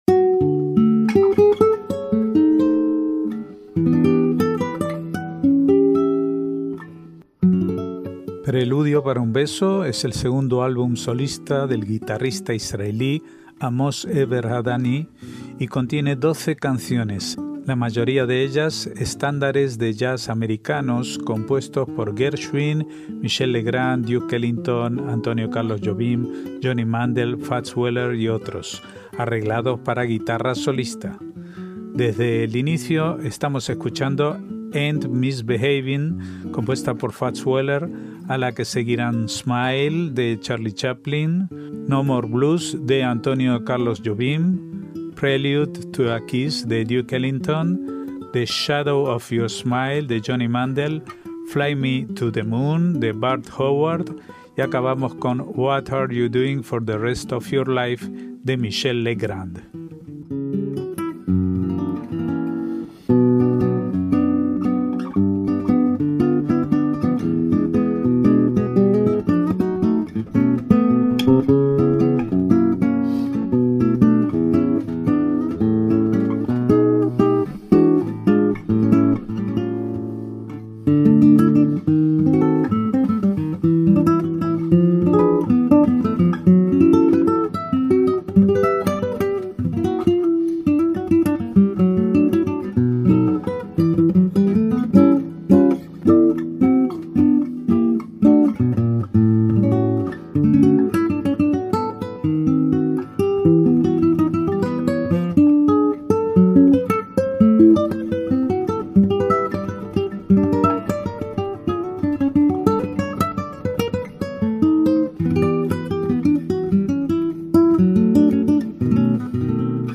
MÚSICA ISRAELÍ
estándares de jazz americanos
arreglados para guitarra solista